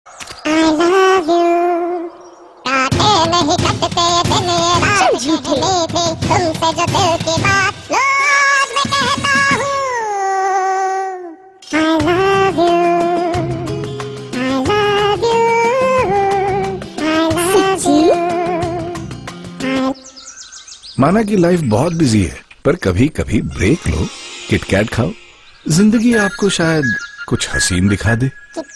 File Category : Free mobile ringtones > > Sms ringtones
File Type : Tv confectionery ads